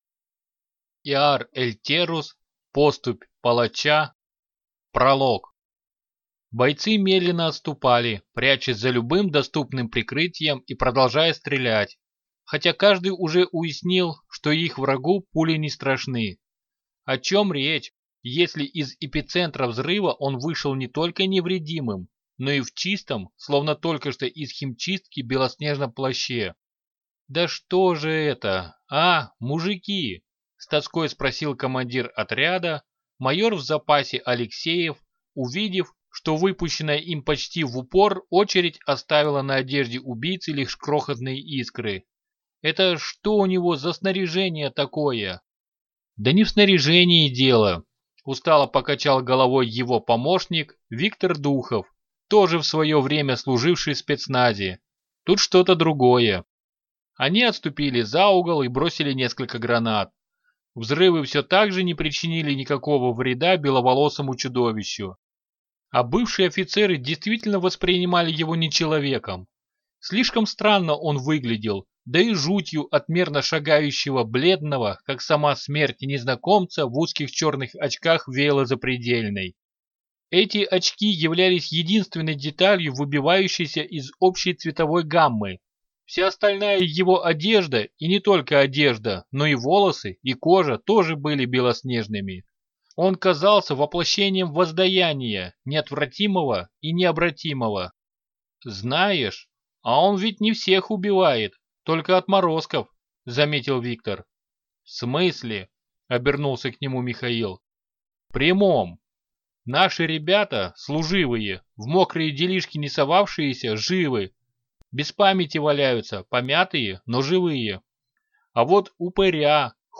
Аудиокнига Поступь Палача | Библиотека аудиокниг
Прослушать и бесплатно скачать фрагмент аудиокниги